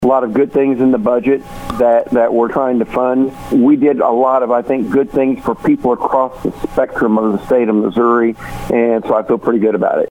State Senator of the 3rd District, Mike Henderson, says he's happy with what legislators have come up with.